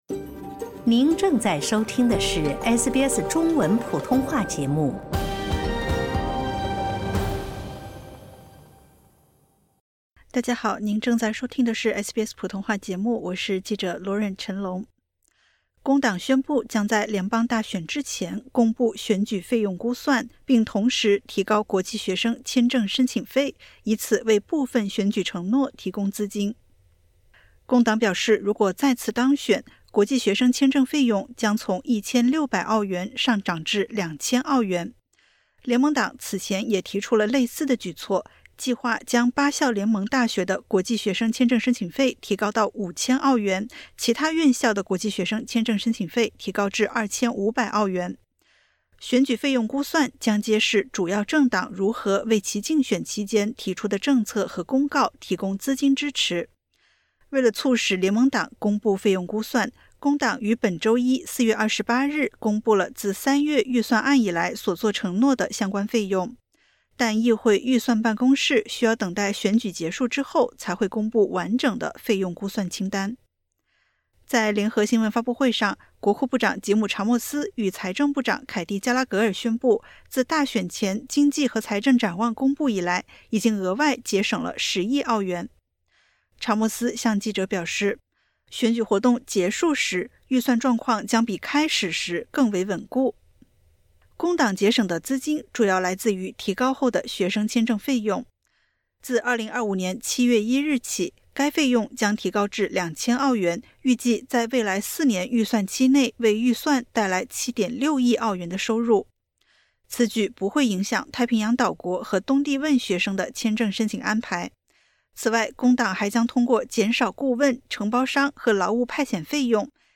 工党宣布，将在联邦大选前公布选举费用估算，并同时提高国际学生签证申请费，以此为部分选举承诺提供资金。点击 ▶ 收听完整报道。